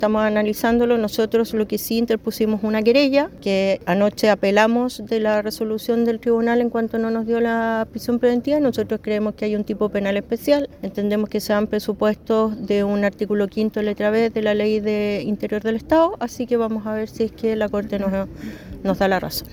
Consultada por Radio Bío Bío, la ministra de Seguridad Pública, Trinidad Steinert, confirmó que apelaron a la resolución del tribunal que negó la prisión preventiva de los tres estudiantes de la Universidad Austral, asegurando que existen antecedentes que permiten decretar la medida cautelar más gravosa.